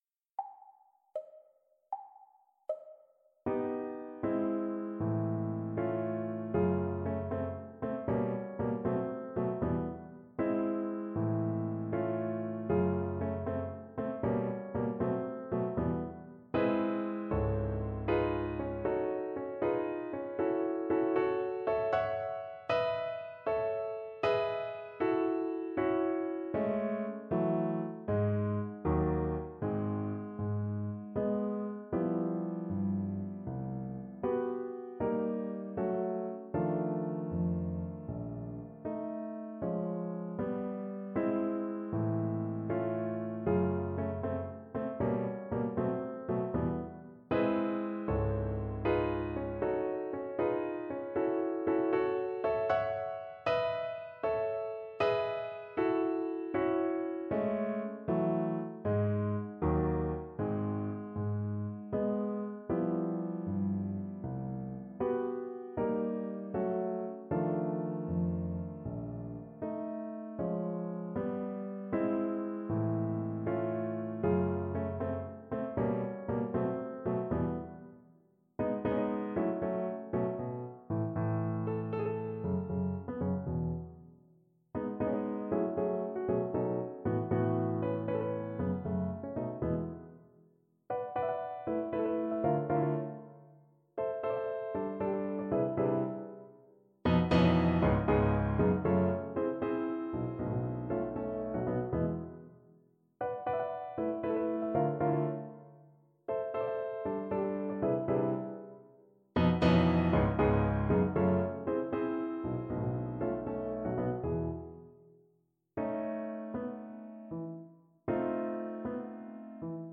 Without Pianist 1